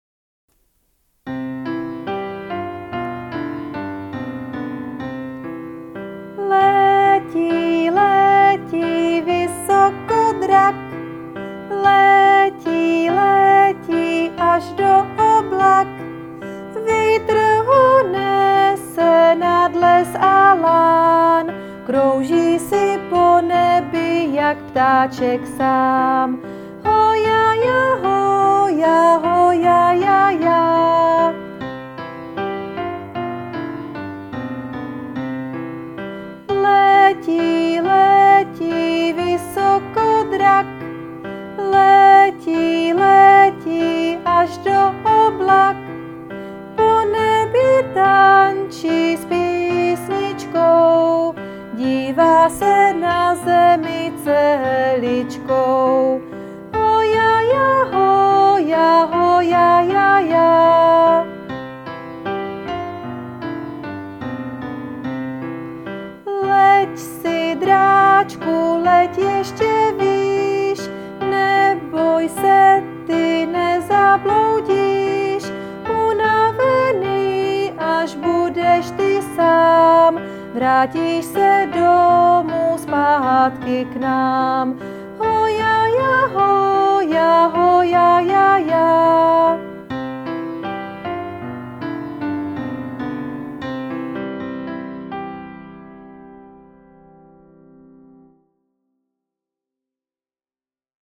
– v C-dur s akordy C-G-F
– studiová kvalita – poslechni si výše ukázku! 🙂
– se zpěvem i bez zpěvu